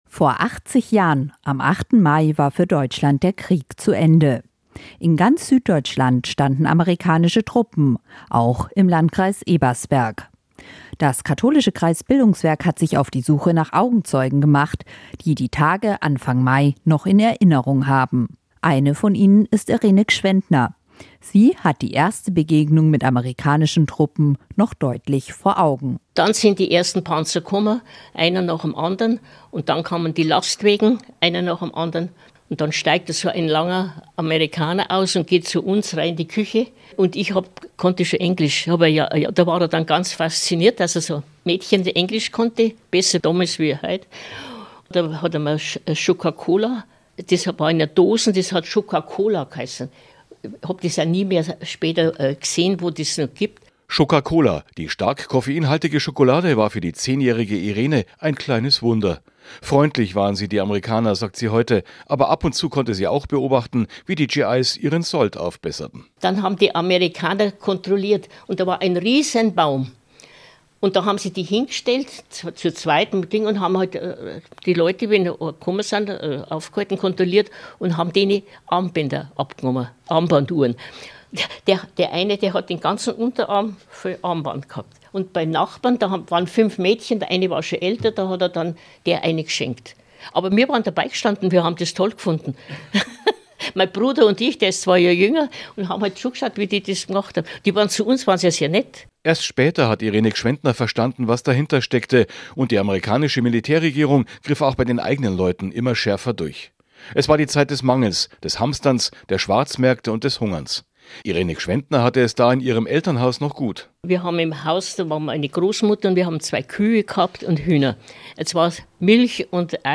Wir haben Interviews mit beeindruckenden Persönlichkeiten geführt, die ihre Erinnerungen teilen.